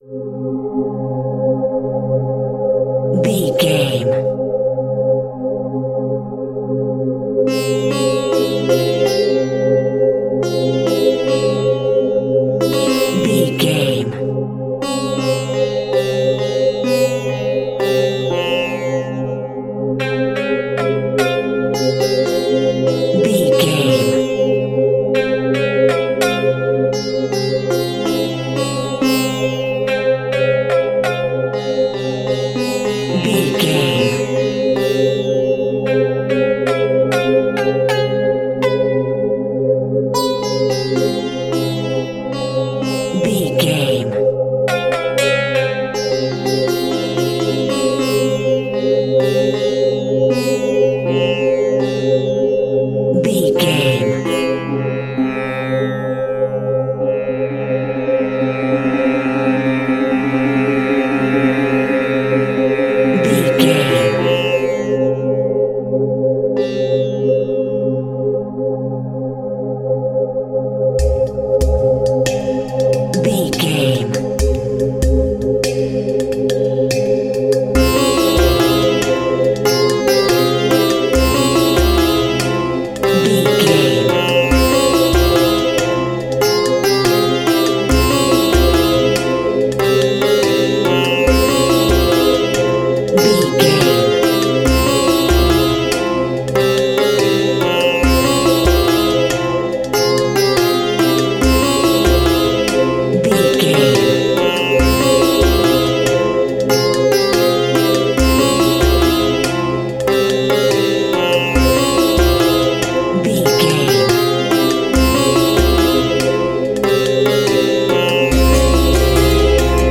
Aeolian/Minor
sitar
bongos
sarod
tambura